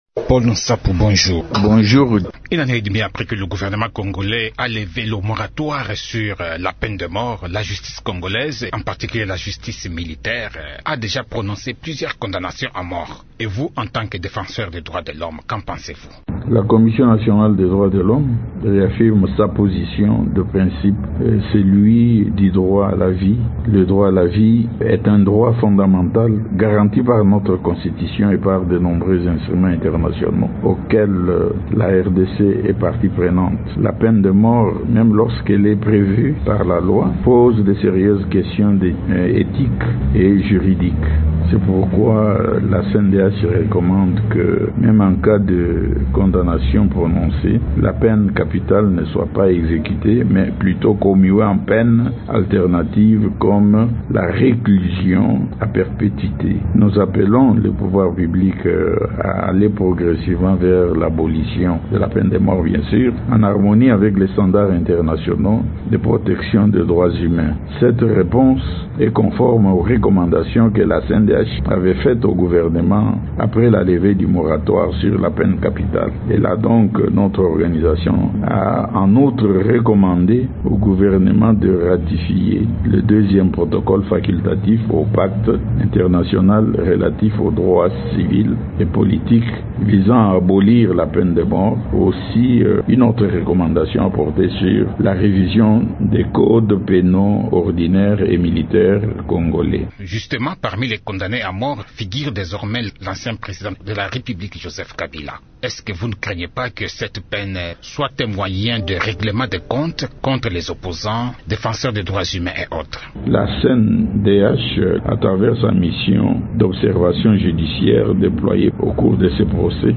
dans un entretien